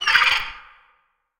Sfx_creature_babypenguin_shudder_01.ogg